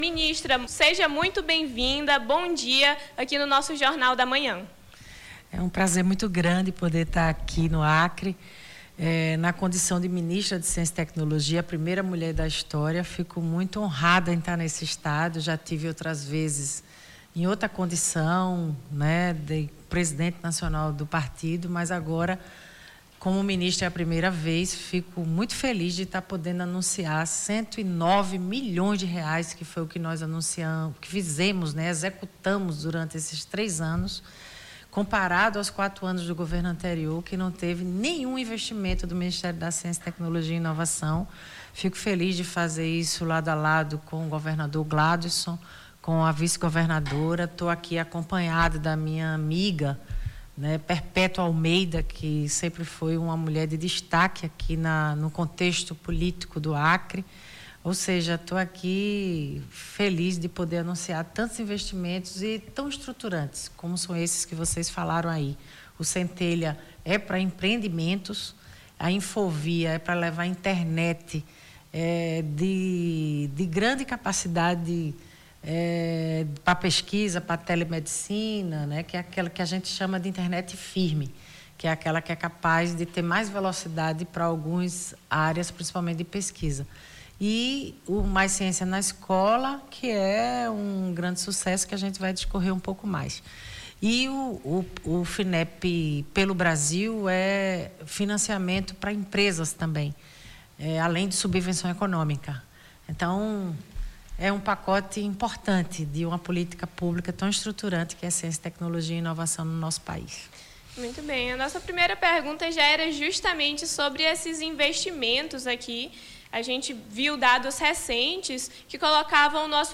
Na manhã desta terça-feira, 03, conversamos com a ministra da Ciência, Tecnologia e Inovação, Luciana Santos, que cumpre agenda no Acre para entrega da Infovia, lançamento do Centelha III e investimentos para o programa Mais Ciência na Escola.
ENTREVISTA